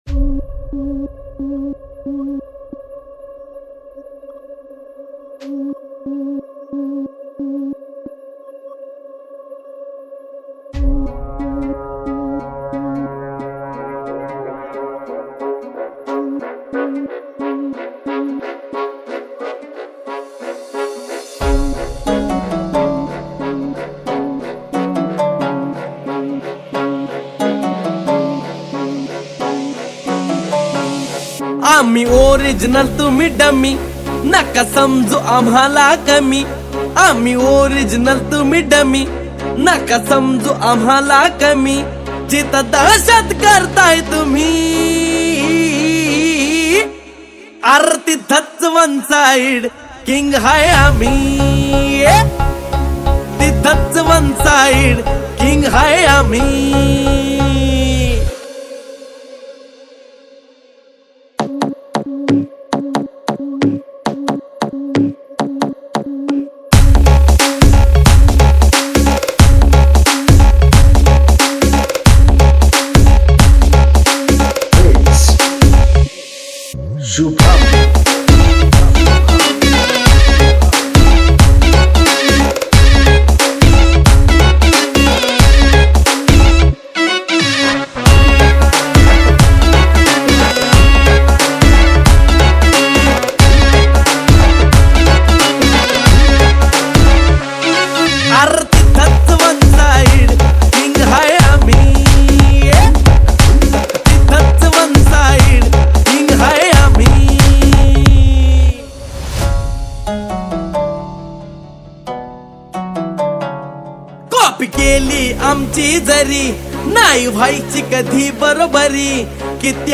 • Category: MARATHI SOUNDCHECK